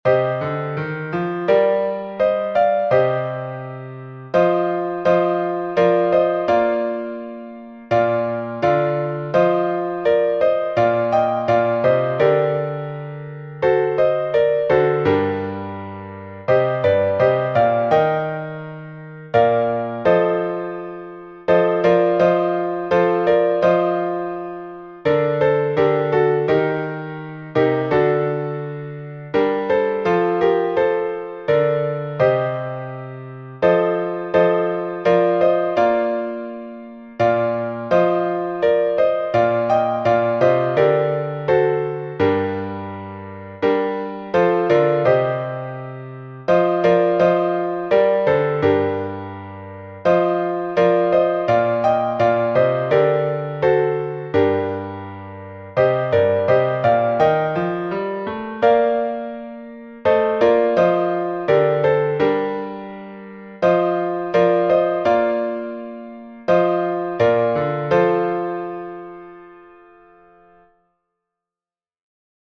глас 2